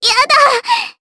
Annette-Vox_Damage_jp_03.wav